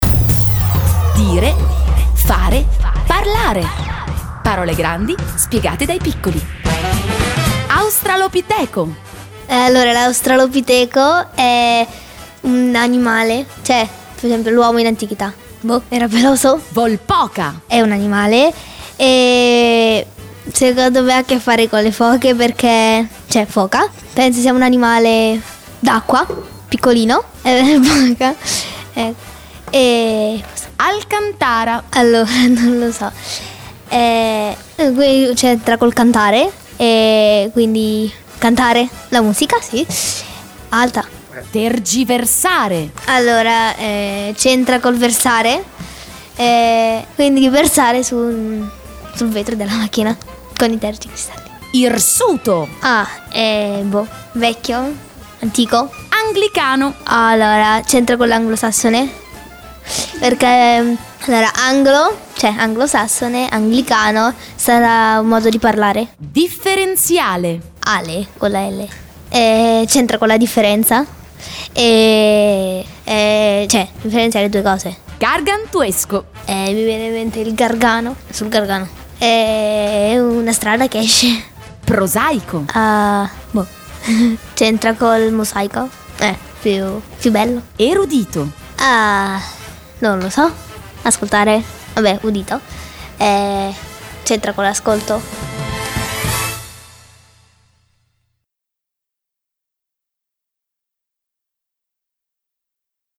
DIREFAREPARLARE-DJ-HIP-HOP.mp3